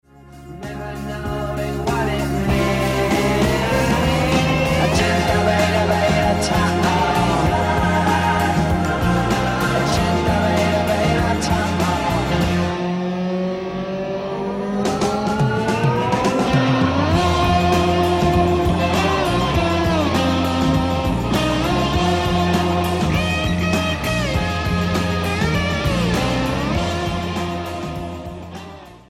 Il frammento del colpo di fulmine: inciso + guitar solo